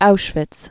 (oushvĭts)